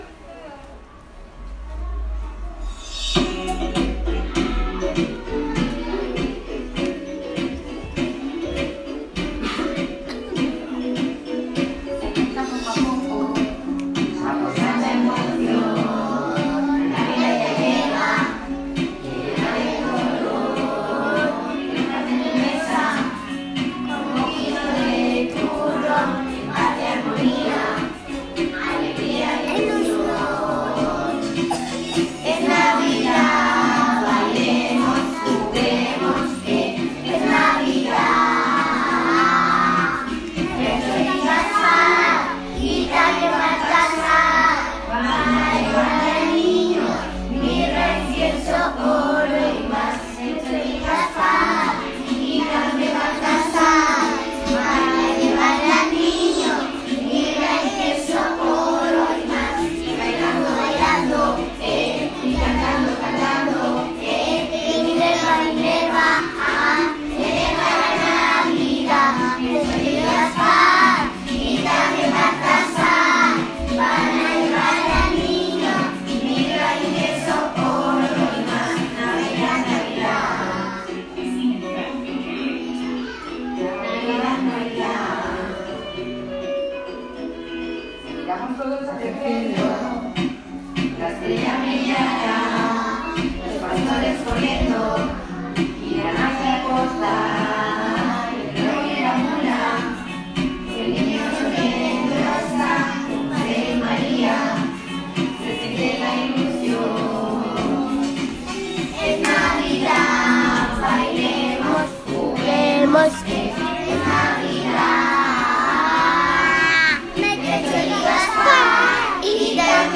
Recital de Navidad
villancico-1.m4a